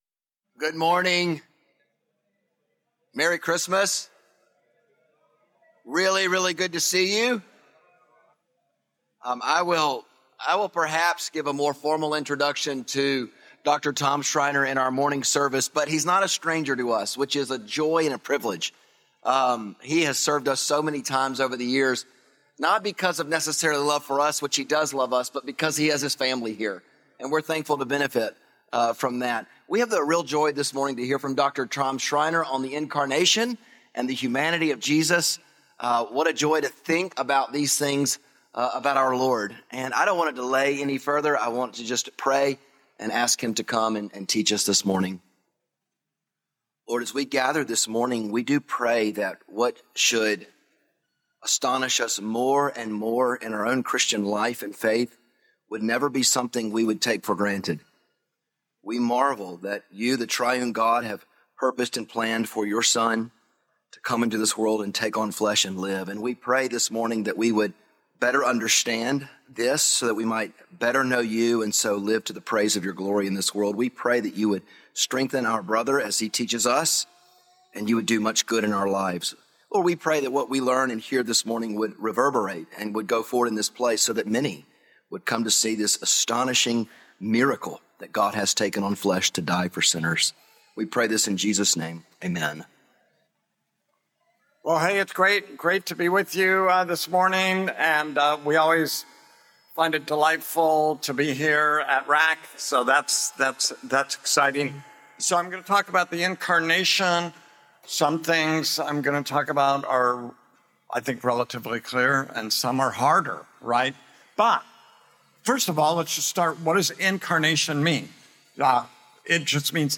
Sermons
Weekly sermons from RAK Evangelical Church